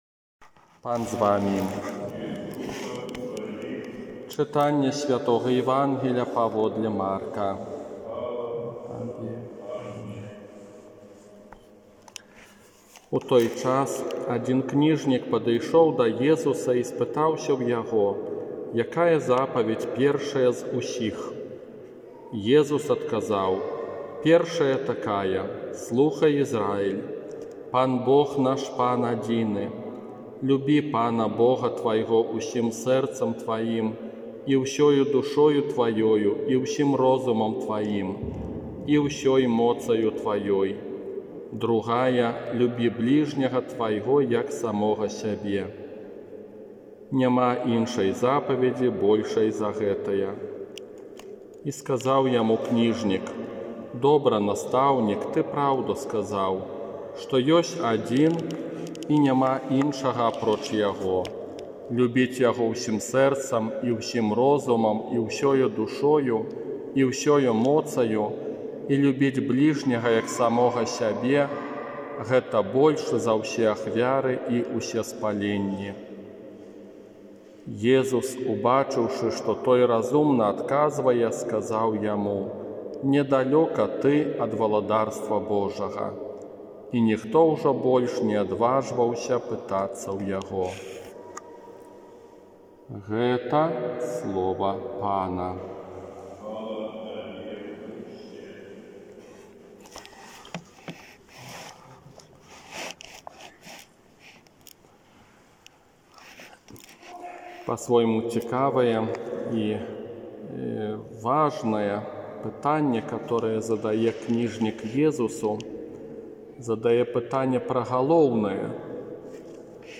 Казанне на трыццаць першую звычайную нядзелю